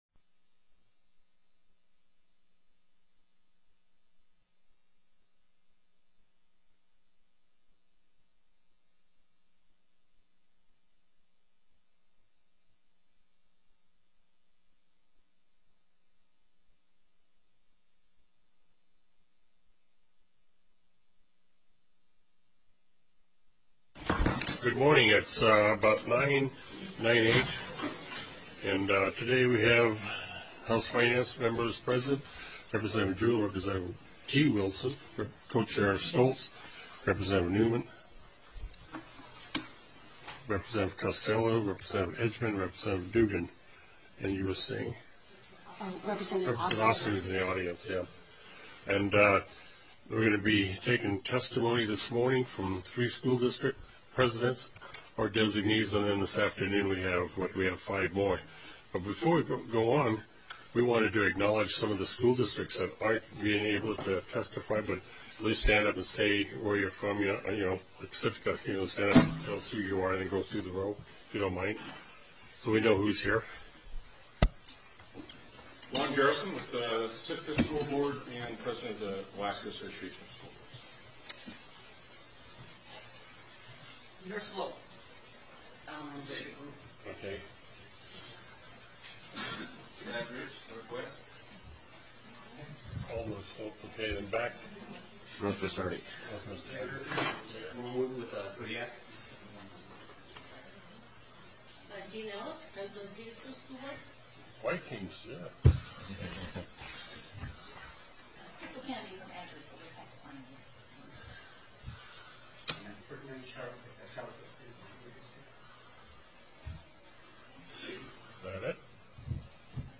Presentation: School Board Presidents or Designated Board Member Testimony